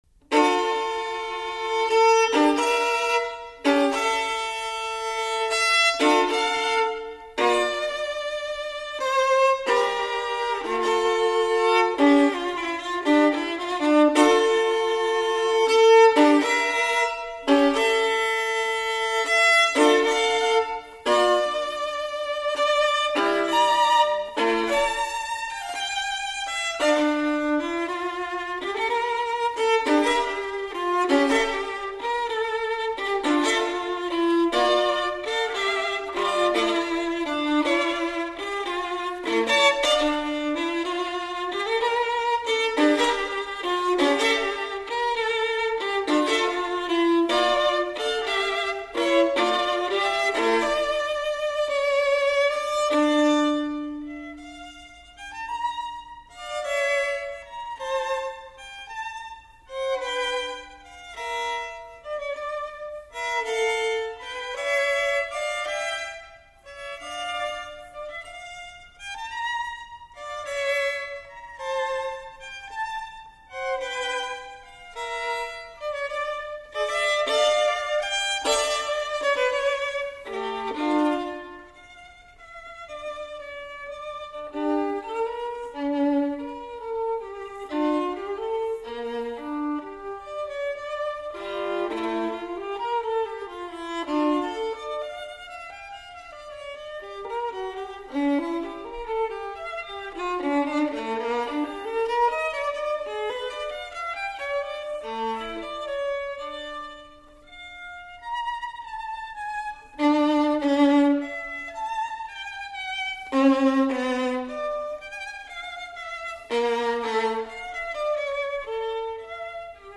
オーディオケーブルによる直接アナログ録音：
ステレオICレコーダーによりリニアPCM無圧縮録音（44.1KHz/16bit）した.WAVファイルをSoundCloudに直接アップロードしています。
chaconne-cable-1.mp3